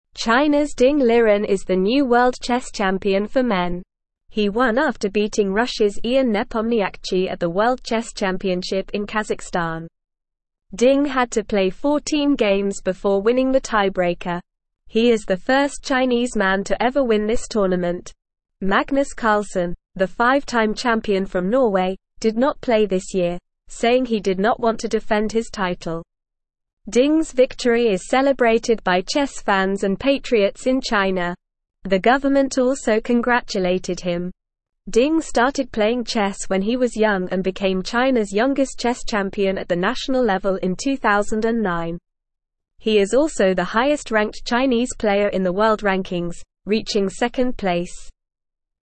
Normal
English-Newsroom-Beginner-NORMAL-Reading-Chinas-Ding-Liren-World-Chess-Champion.mp3